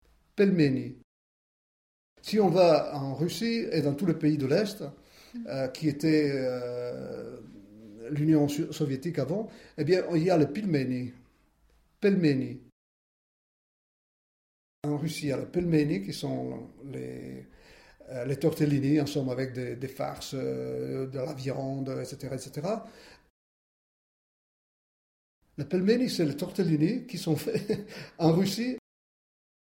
uitspraak Pelmenis.